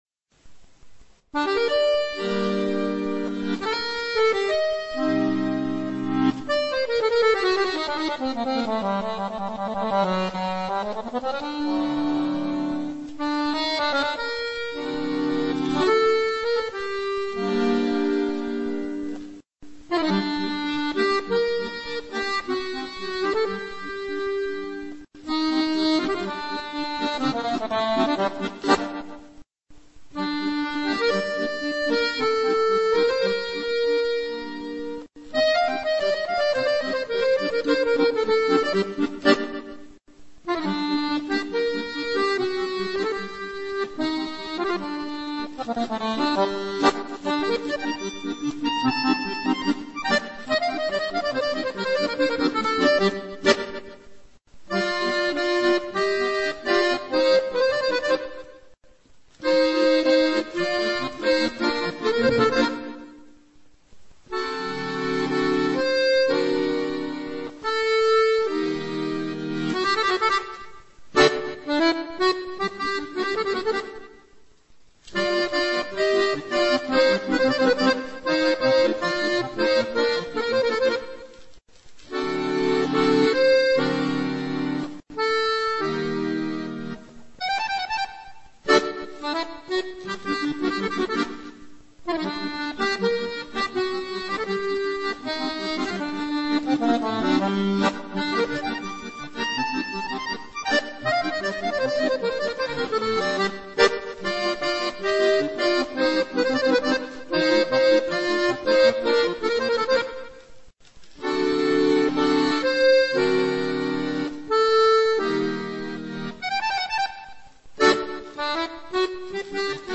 Rock, jazz, polcas, bailes de Auvernia (bourrées), valses, java, tango, madison, fox-trot, gaita, folk, musicas Tex-Mex, cajun y zydéco, musicas traditionnales, swing, gitanos y swing-musette, etc; el accordeón se presta de buen grado a todas las clases musicales.
clásico hace clic